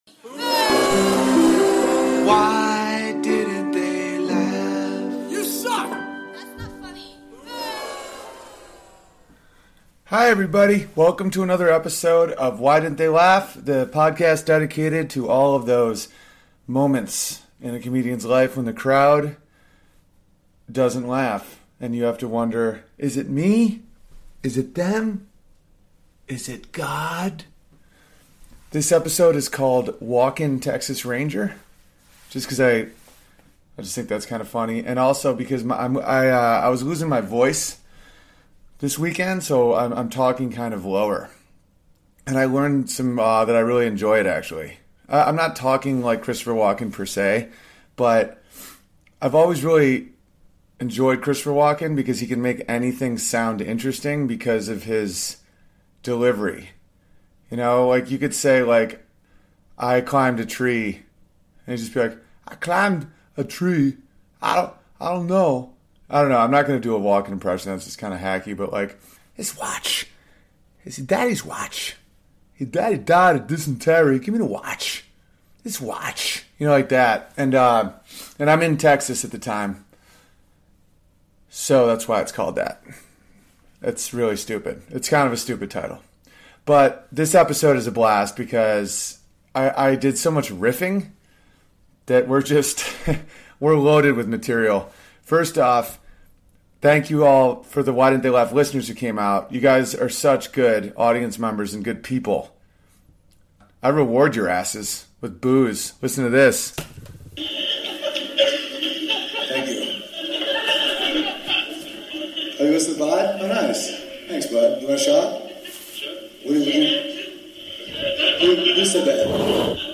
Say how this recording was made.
This week in houston I try a new joke that bombs. I was influenced by two books I read this week.